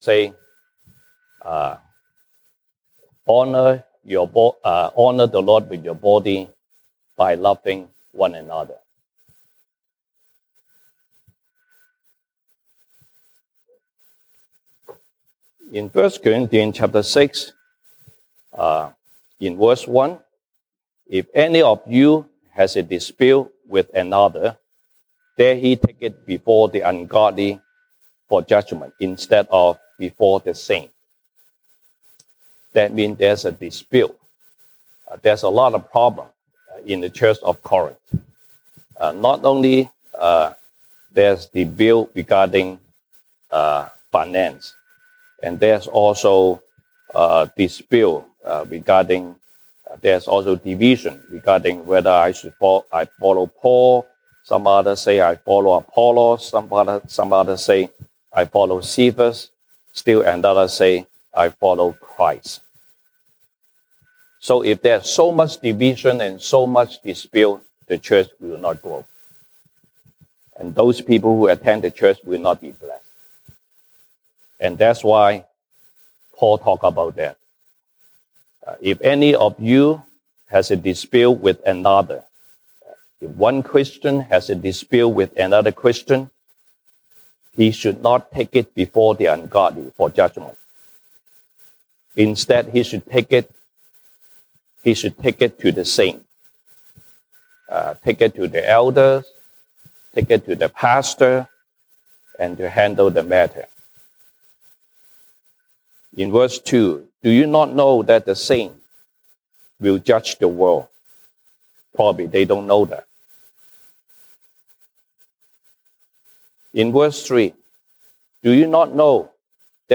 西堂證道 (英語) Sunday Service English: Honor God with our body